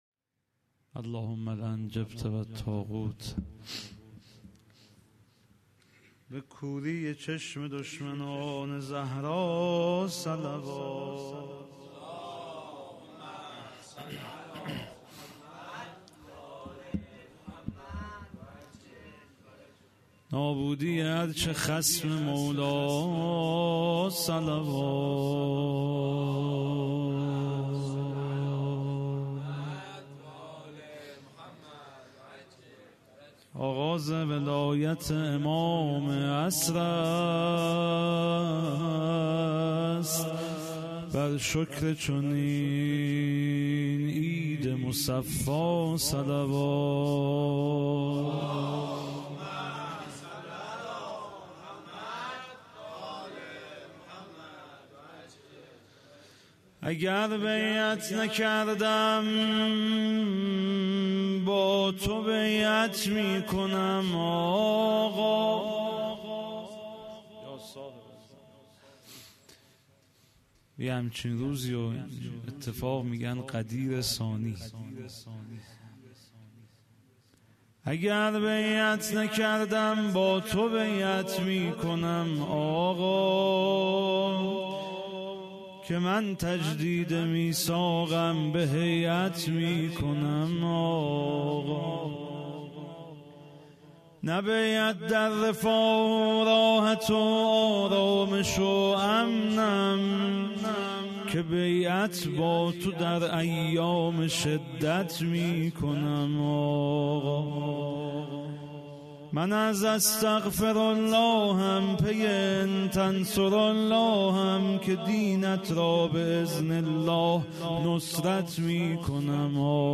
مدیحه خوانی بیعت میکنم آقا
مراسم هفتگی‌ سالروز آغاز امامت امام زمان عجل الله تعالی